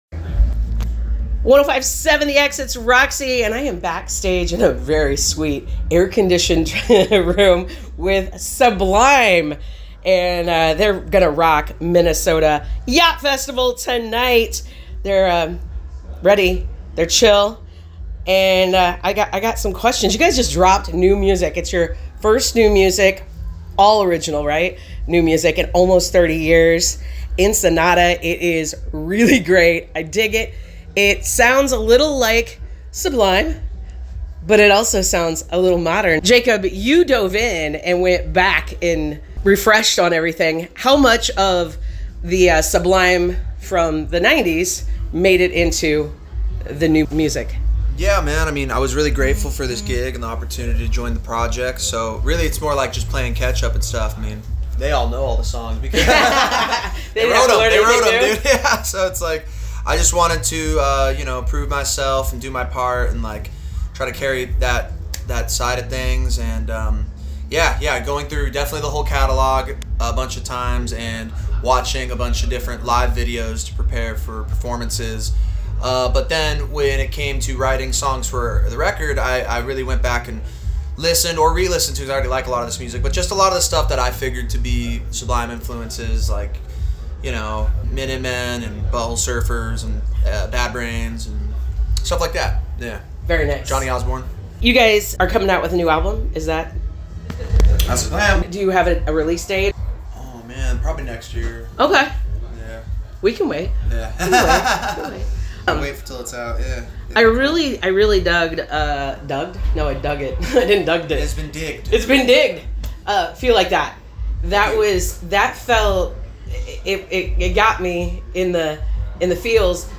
<<<<<Backstage w/ Sublime & their sick day 3 set
sublime-interview-myc-fest.wav